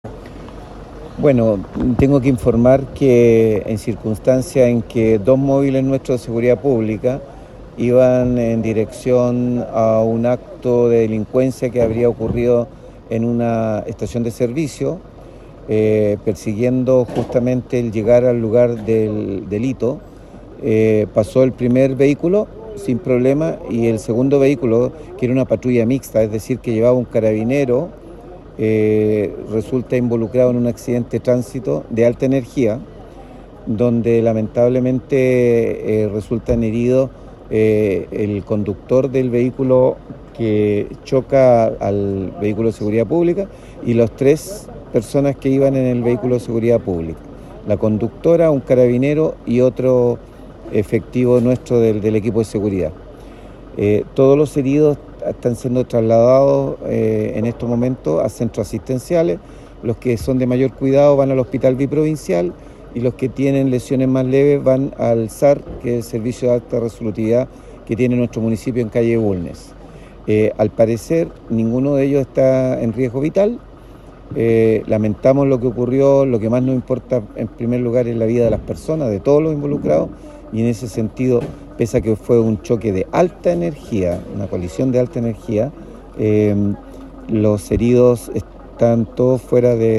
El alcalde de Quillota, Luis Mella se hizo presente en la emergencia en donde conoció en detalle la situación y realizó un primer informe preliminar: